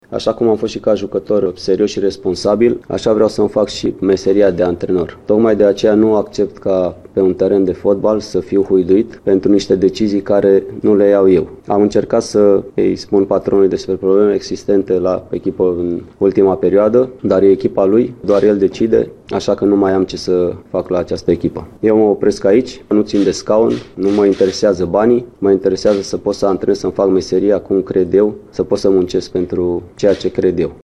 02aug-07-Voce-Bogdan-Andone-demisie.mp3